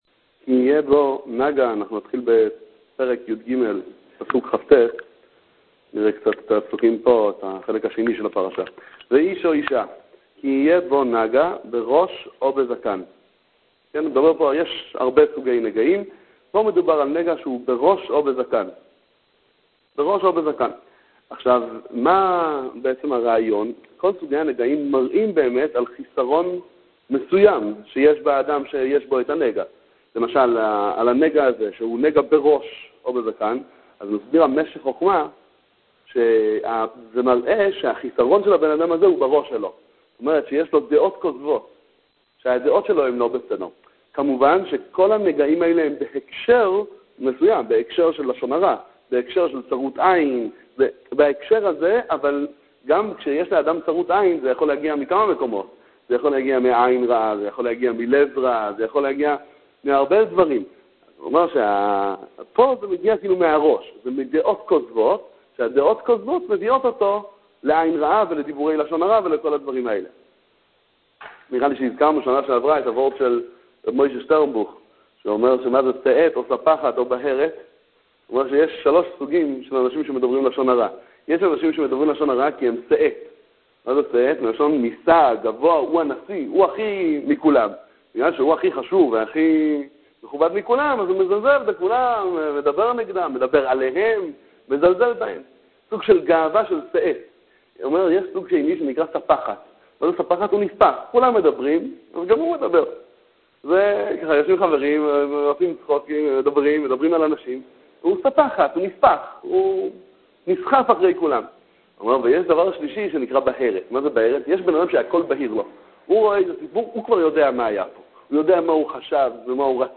שיעורי תורה על פרשת השבוע, אוסף דברי תורה ווארטים וסיפורים